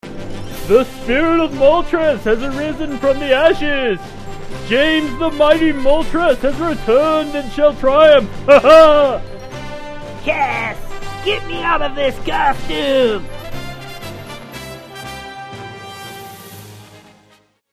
Musashi and Kojiro's motto is from the song "Rocketto Dan Yo! Eien Ni" and Kosaburou and Yamato's motto is from The Breeding Center Secret episode. In case you haven't figured it out yet, these are the Japanese mottos not the English ones.